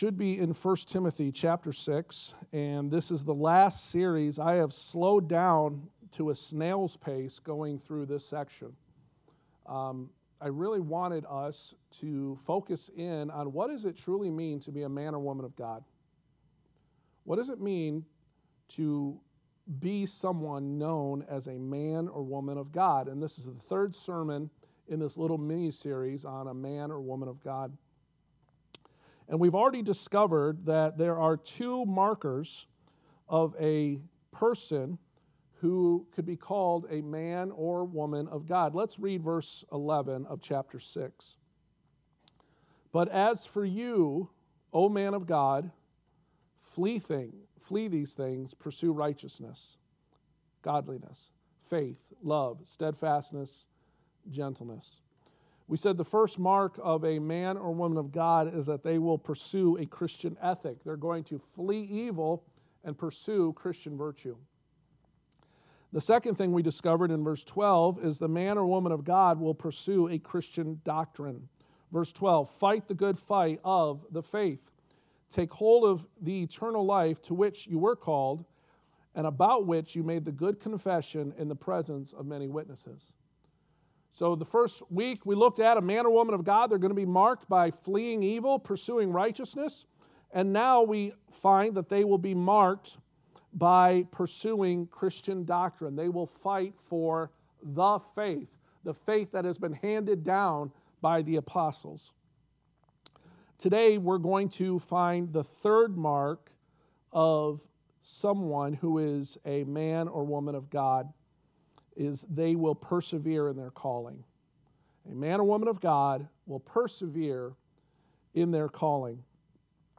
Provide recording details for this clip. The Pastoral Epistles Passage: 1 Timothy 6:13-16 Service Type: Sunday Morning « The Man or Woman of God